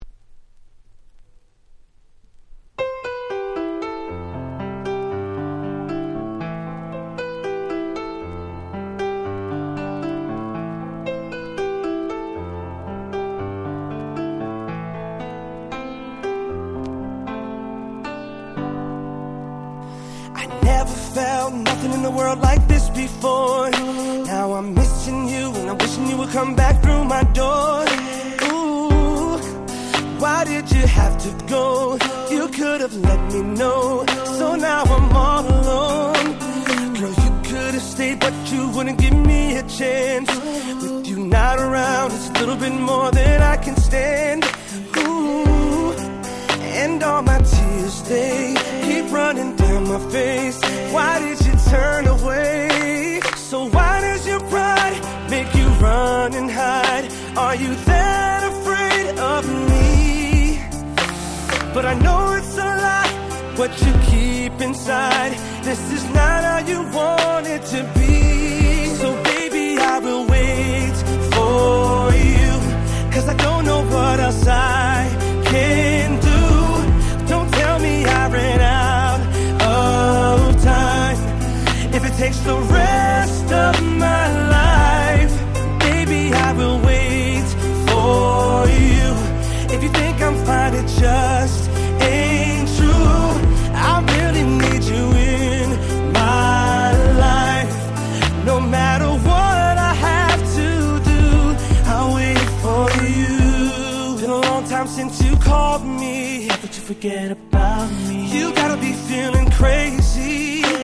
07' Super Hit R&B !!